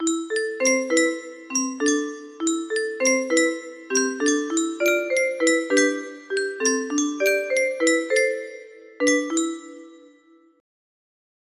Practice 1 music box melody